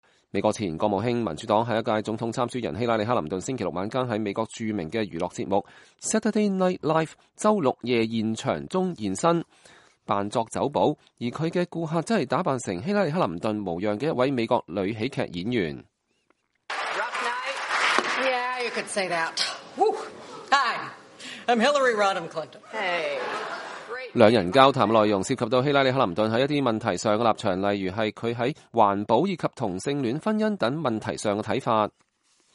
美國前國務卿、民主黨下一屆總統參選人希拉里克林頓星期六晚間在美國著名的娛樂節目Saturday Night Live – “週六夜現場”中現身，扮作酒保，而她的顧客則是打扮成希拉里克林頓模樣的一位美國女喜劇演員。
兩人交談的內容涉及到希拉里克林頓在一些問題上的立場，諸如她在環保、以及同性戀婚姻等問題上的看法。節目進行期間，希拉里還演繹了一番共和黨總統參選人之一川普的模樣，並且諷刺了自己在公眾當中不是那麼具有“親和力”的形象。